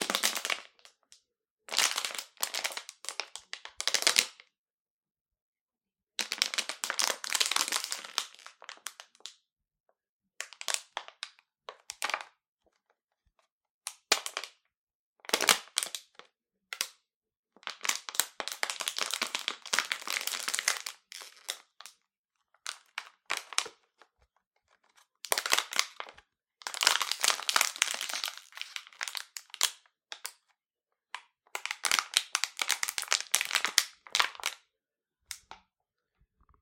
Crunch 7
描述：Various Triscuits crushed by hand. Recorded using a Zoom H2.
标签： cracker crunch crush
声道立体声